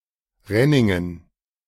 Renningen (German pronunciation: [ˈʁɛnɪŋən]